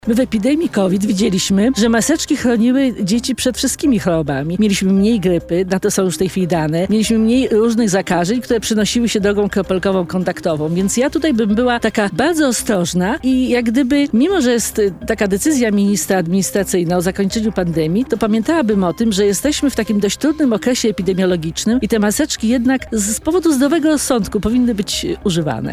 W Porannej Rozmowie Radia Centrum przypomniała, że noszenie maseczek zabezpieczało nie tylko przed covidem.